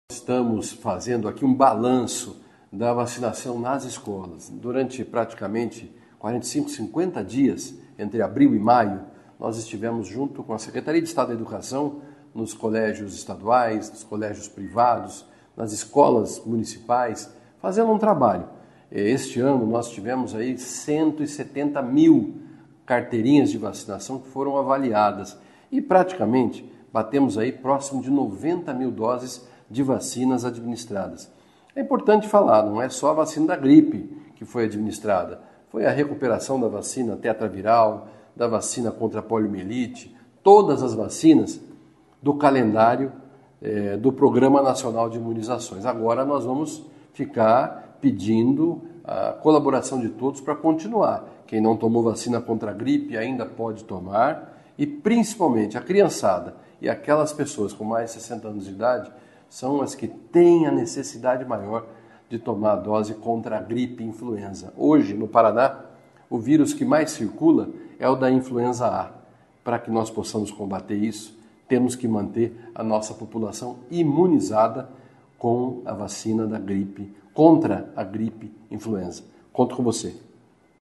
Sonora do secretário Estadual da Saúde, Beto Preto, sobre os resultados da campanha de vacinação nas escolas | Governo do Estado do Paraná